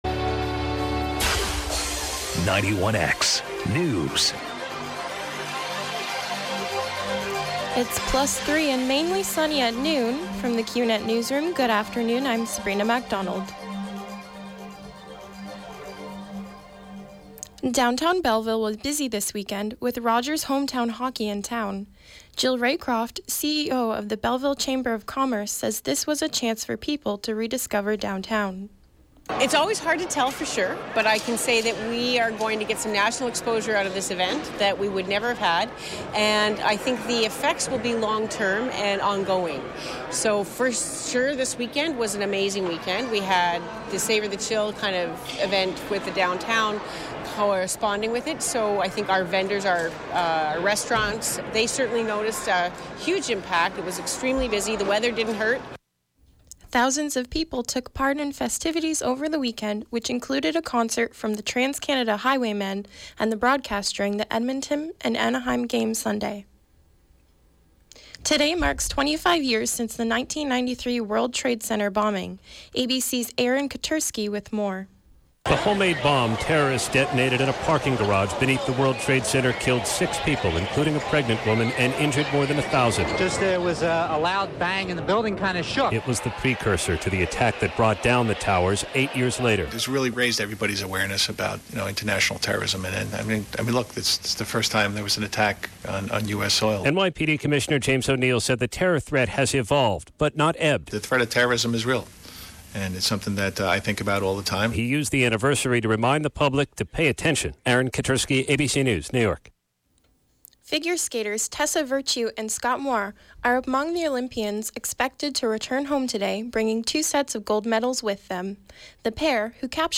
91X Newscast: Monday, Feb. 26, 2018, noon